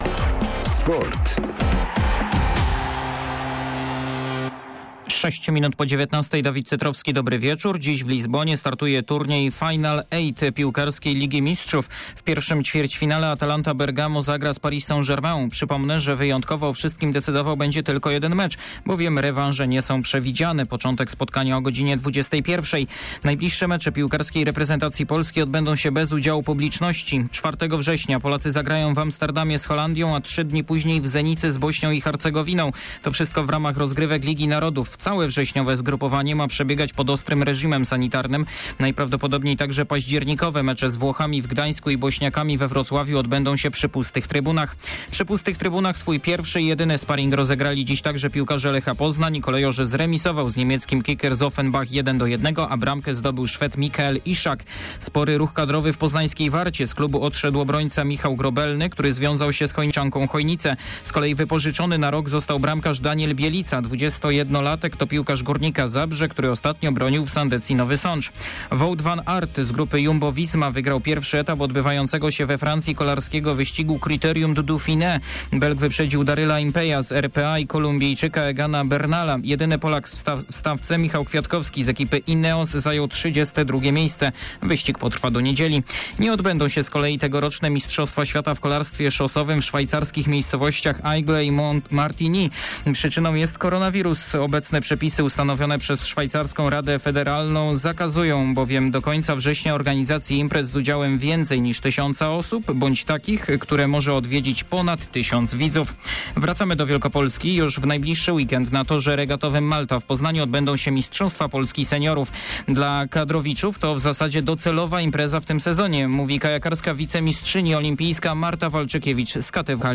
12.08. SERWIS SPORTOWY GODZ. 19:05